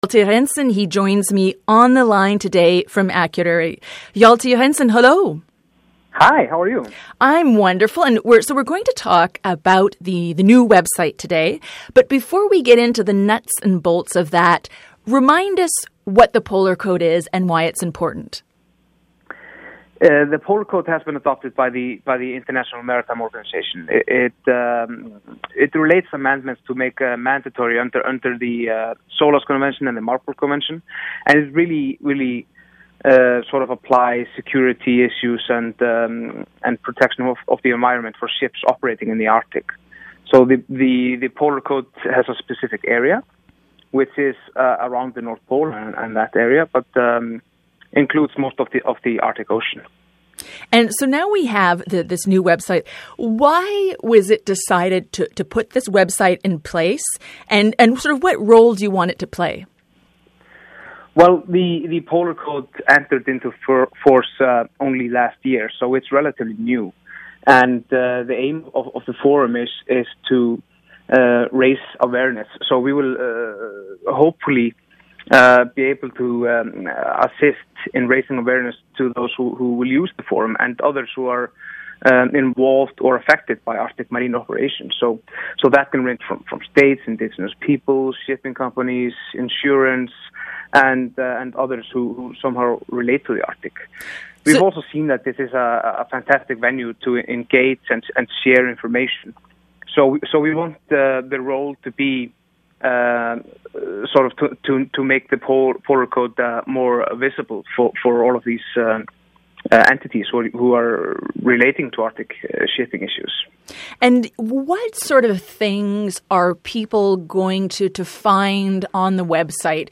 Feature Interview: Tackling Arctic and Antarctic shipping